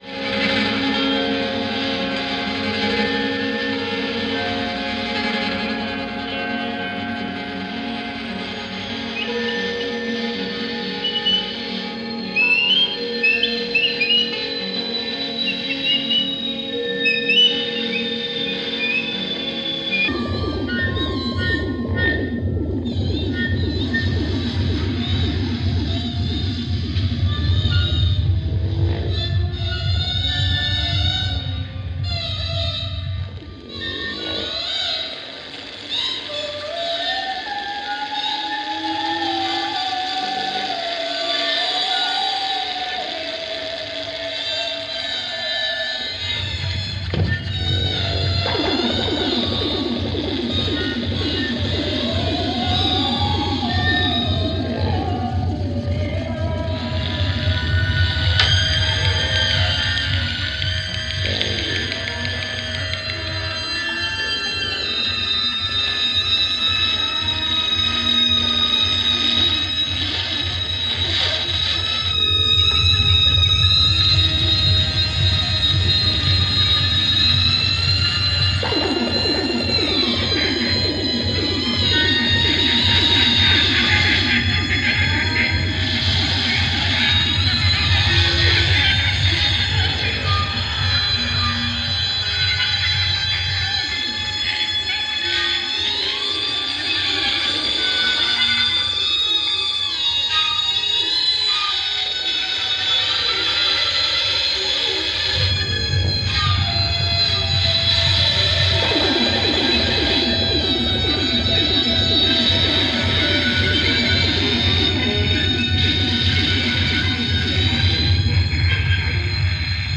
improvisation in large ensembles